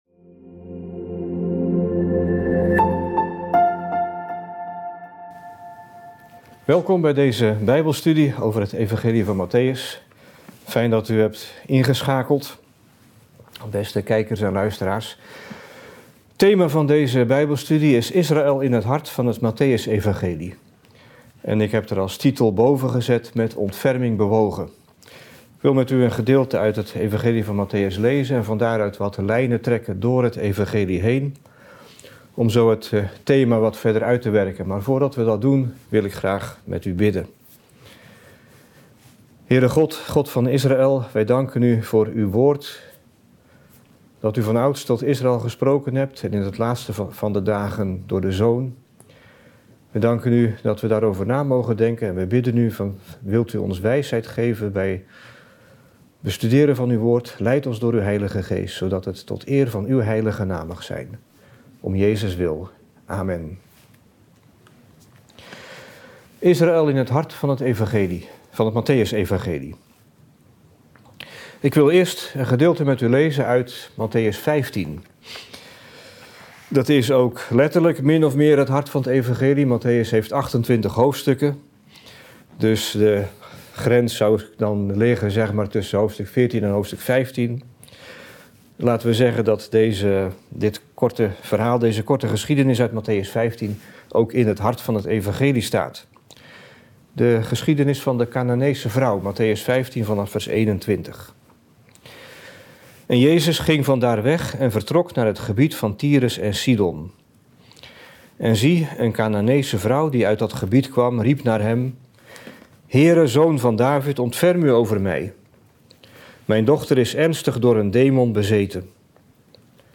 Christenen voor Israël Israël in het hart van het Evangelie van Mattheüs • Bijbelstudie drs.